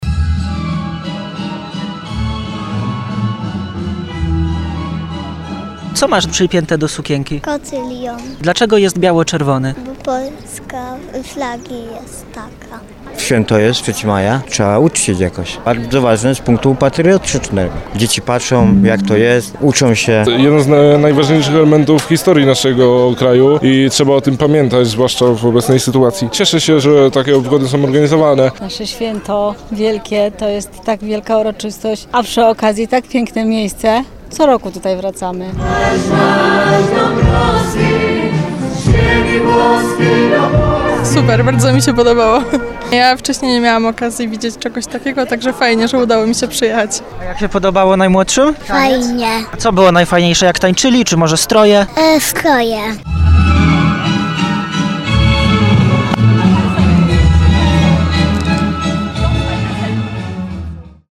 W Miasteczku Galicyjskim w Nowym Sączu było sporo atrakcji przygotowanych z okazji 233. rocznicy uchwalenia Konstytucji 3 Maja.
Zapytaliśmy uczestników o to czym jest dla nich Święto Konstytucji 3 Maja i o wrażenia z występów.